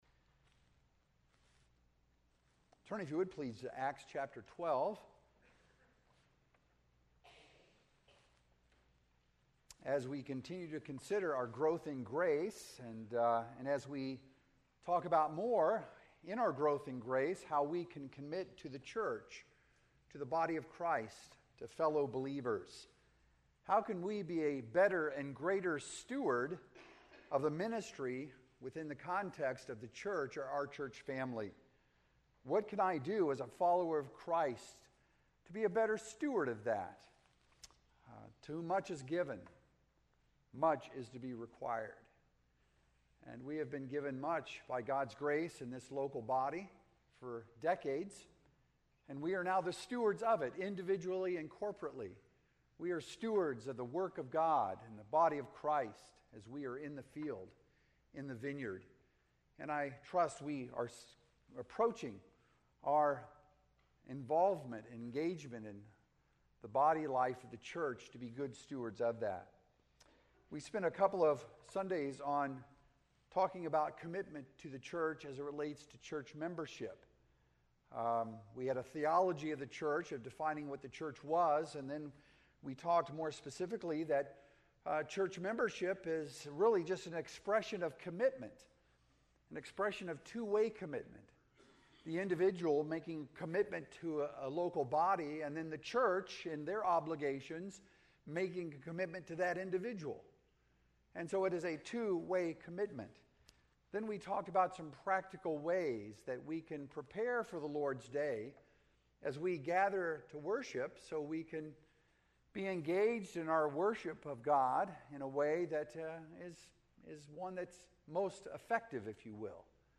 Sermons - Emmanuel Baptist Church
Sunday Morning Worship